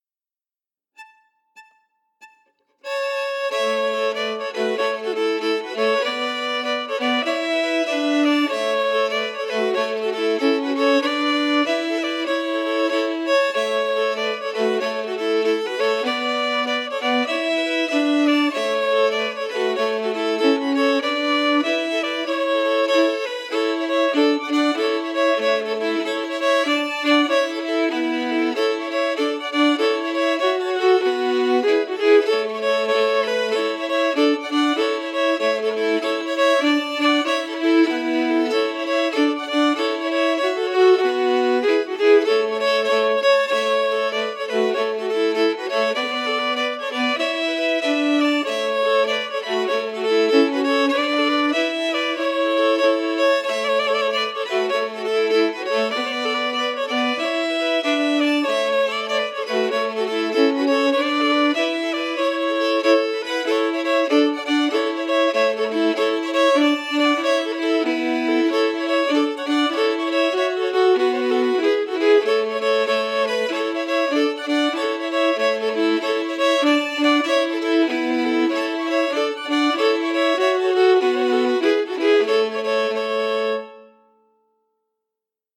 Key: A
Form: Jig
Harmony emphasis